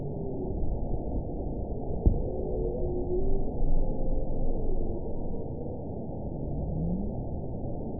event 920971 date 04/21/24 time 00:16:02 GMT (1 year, 1 month ago) score 9.26 location TSS-AB05 detected by nrw target species NRW annotations +NRW Spectrogram: Frequency (kHz) vs. Time (s) audio not available .wav